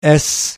Ääntäminen
Ääntäminen Tuntematon aksentti: IPA: /ʔɛs/ Haettu sana löytyi näillä lähdekielillä: saksa Käännöksiä ei löytynyt valitulle kohdekielelle. S on sanan Süd lyhenne.